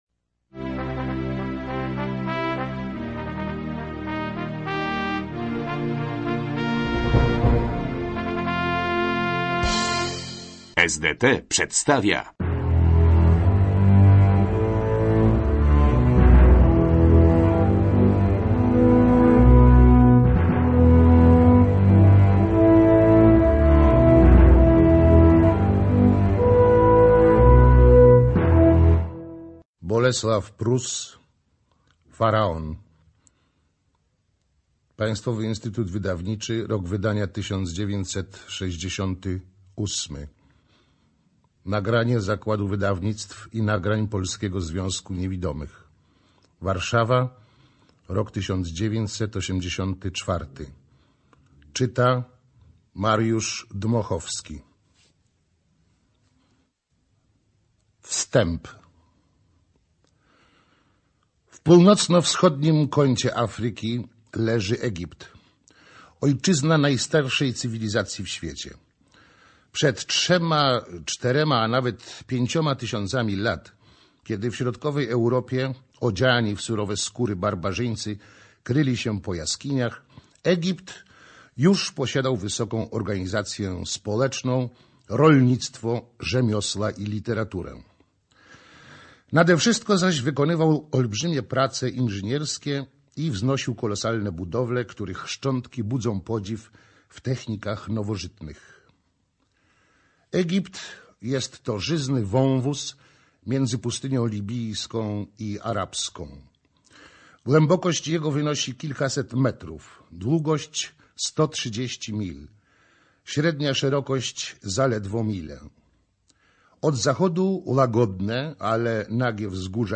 ЧитаетДмоховски М.